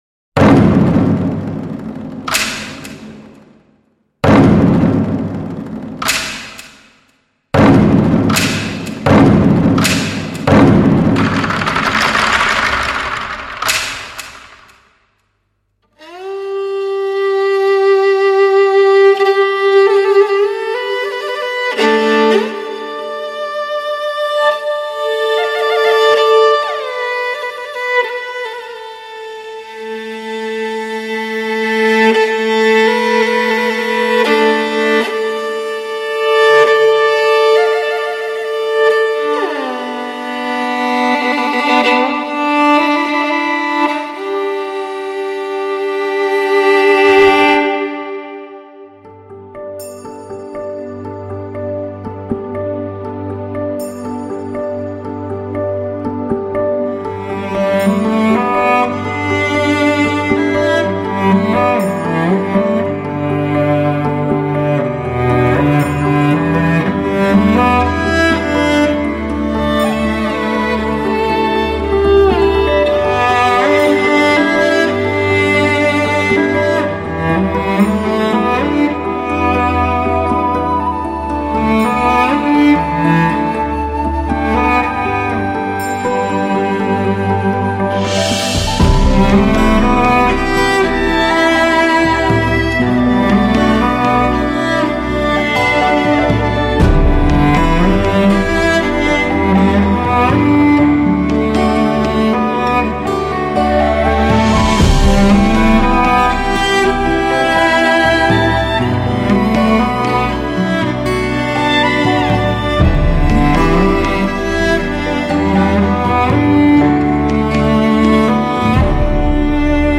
马头琴
一盏灯，一盅咖啡，伴着舒缓而优雅的旋律。
低沉，却没有丝毫的厚重，融化了妩媚和沧桑，缓缓的、姿意的流淌；
大提琴与马头琴